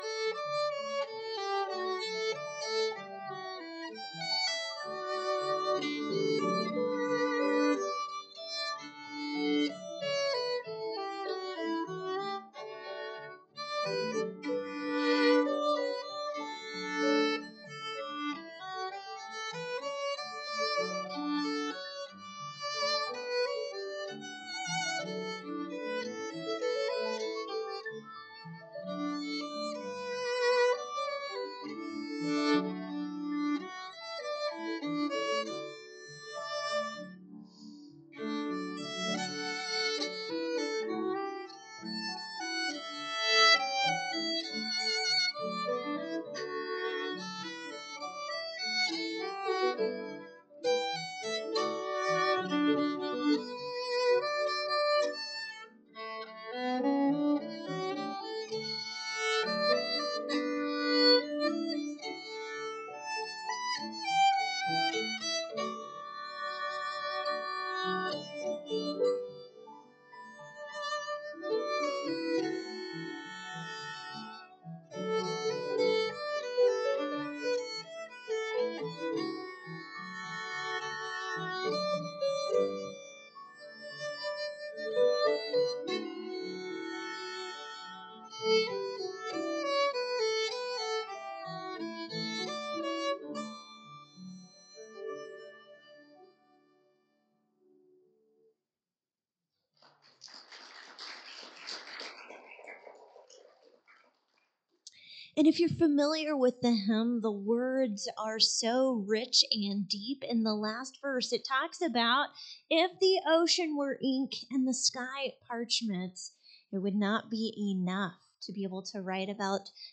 The Chengs in Concert